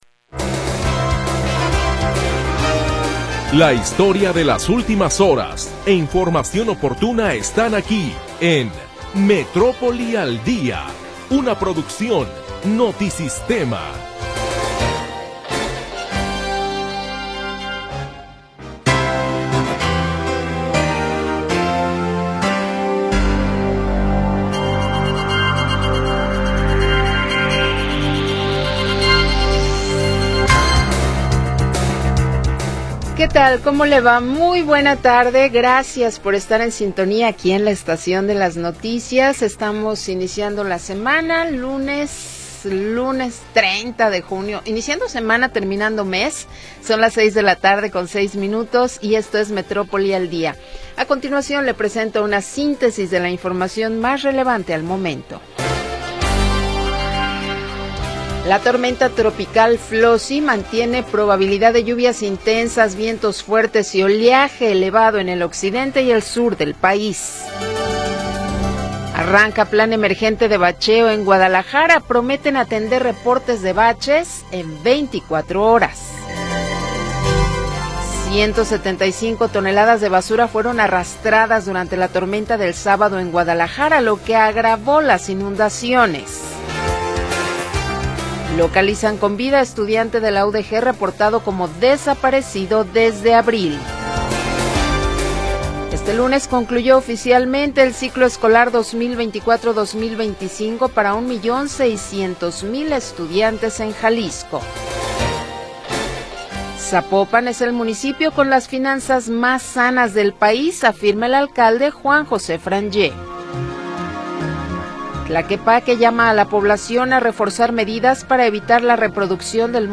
Primera hora del programa transmitido el 30 de Junio de 2025.